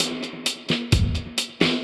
Index of /musicradar/dub-designer-samples/130bpm/Beats
DD_BeatB_130-01.wav